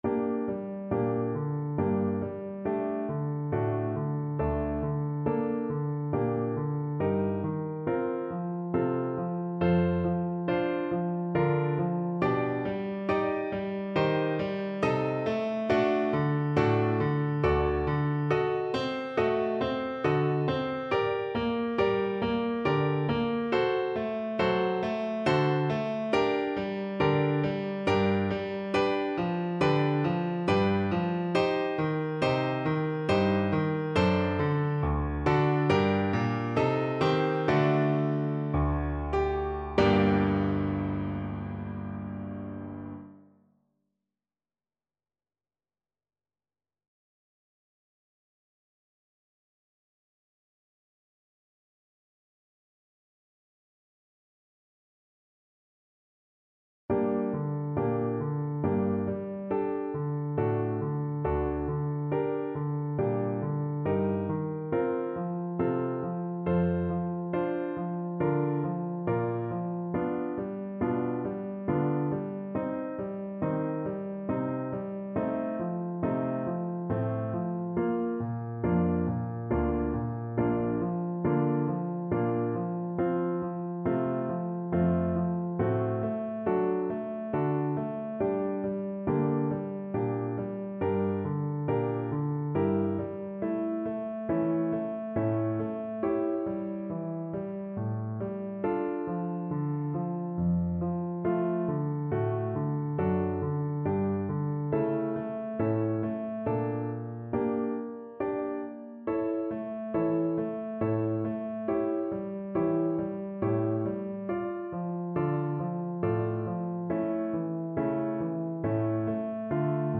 Andante stretto (=c.69)
Classical (View more Classical Soprano Voice Music)